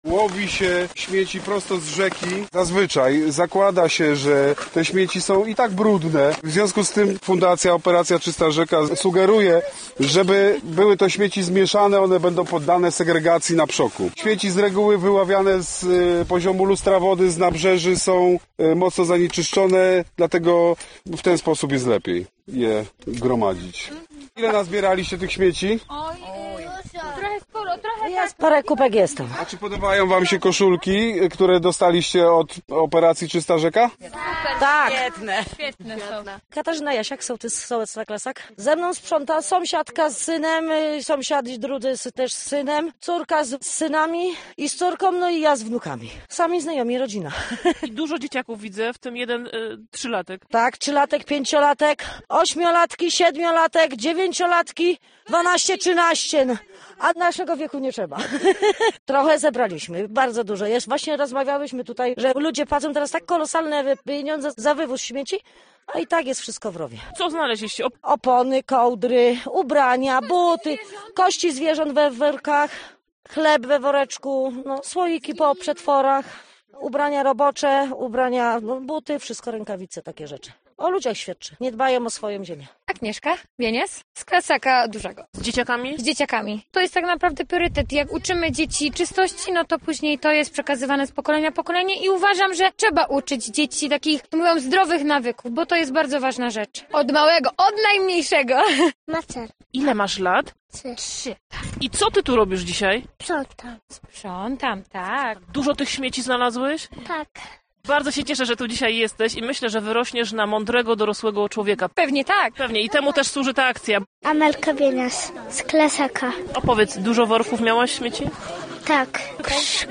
Radio Ziemi Wieluńskiej było patronem medialnym akcji. Dziś po 19:00 zaprosimy na ponad półgodzinny reportaż zrealizowany podczas jej trwania.